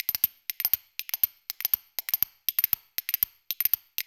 Spoons